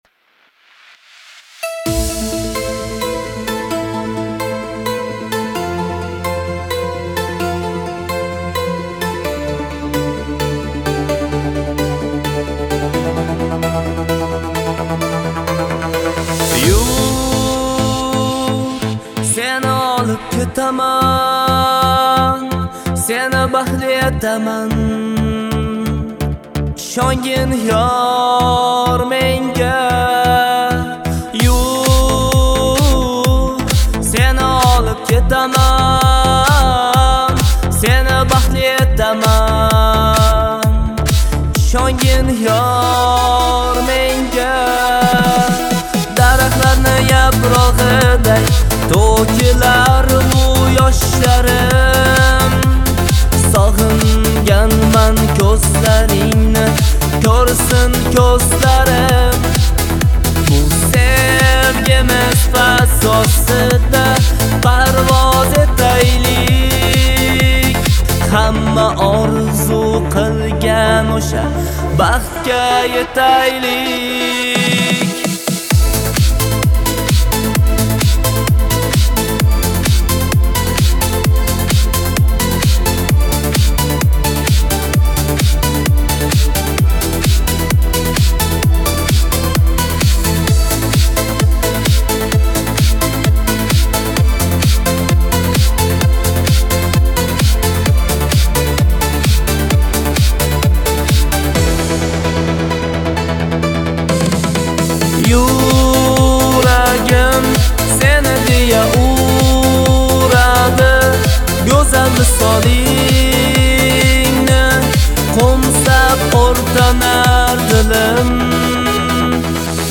Трек размещён в разделе Узбекская музыка / Поп.